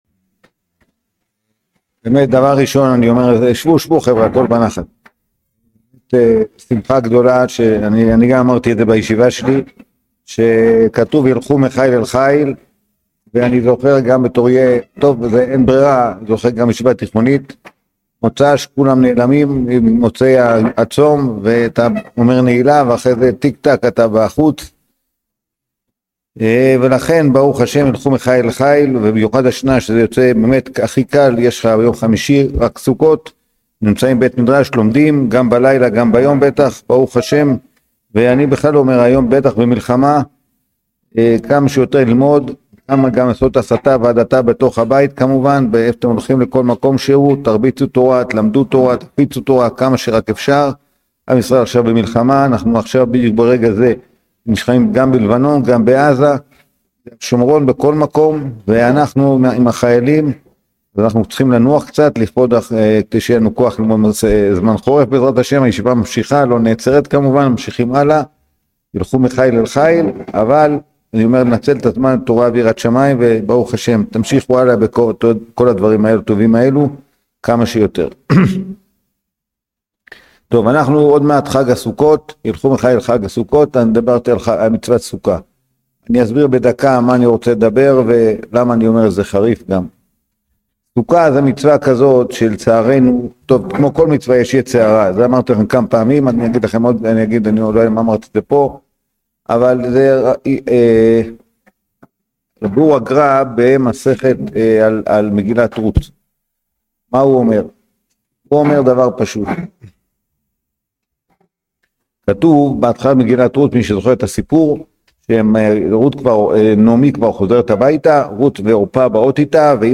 שיעור הכנה לסוכות | יום עיון סוכות